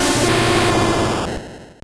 Cri de Typhlosion dans Pokémon Or et Argent.